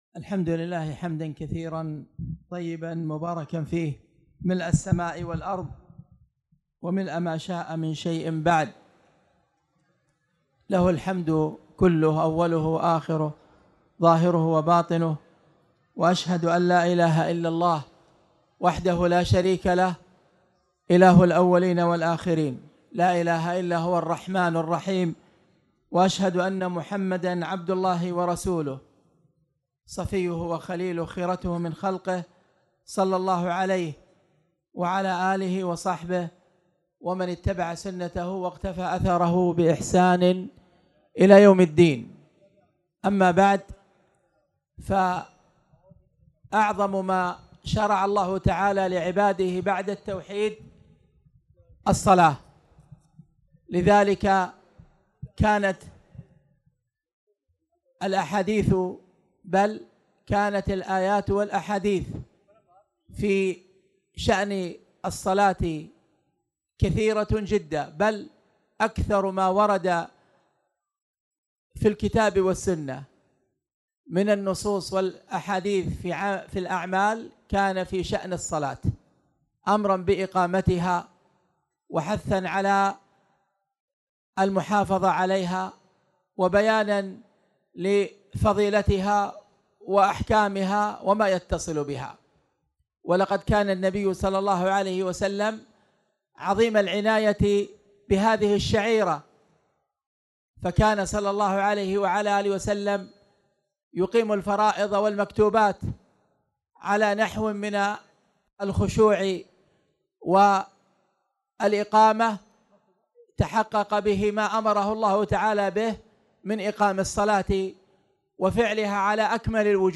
تاريخ النشر ١٢ صفر ١٤٣٨ هـ المكان: المسجد الحرام الشيخ